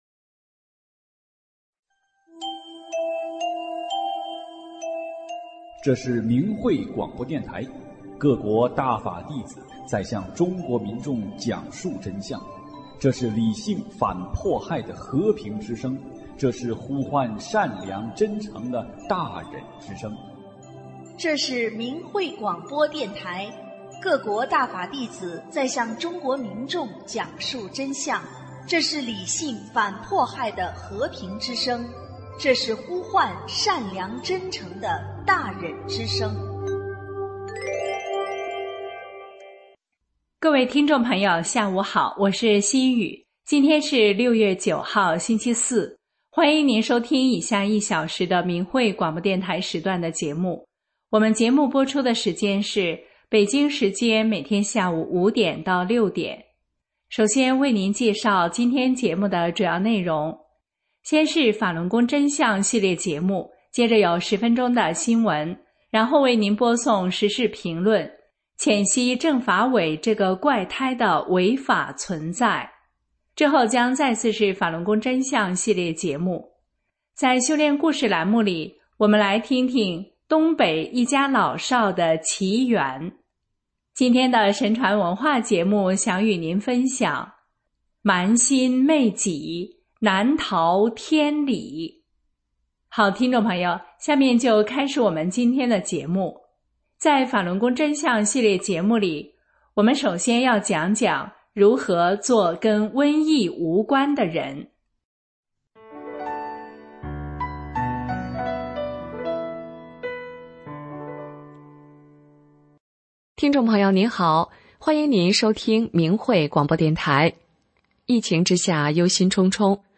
琵琶曲